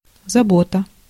Ääntäminen
IPA: [ɛ̃.kje.tyd]